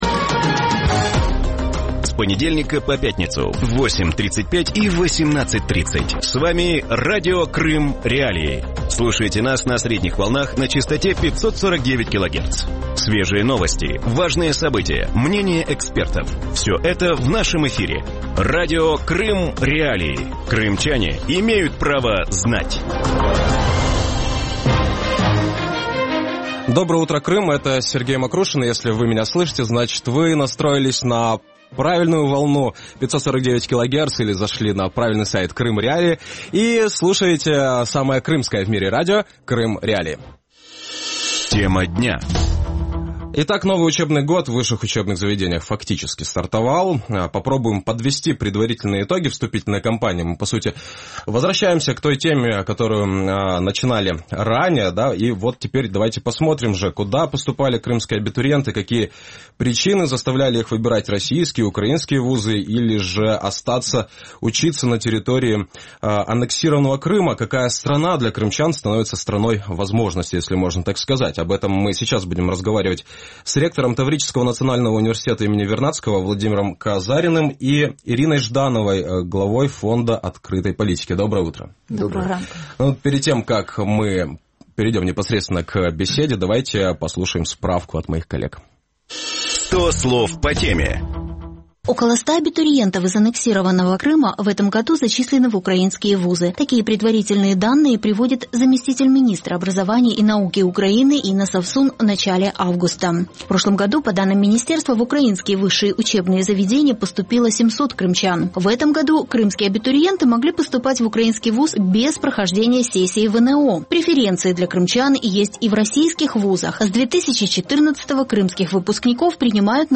Про це він розповів у ранковому ефірі Радіо Крим.Реалії.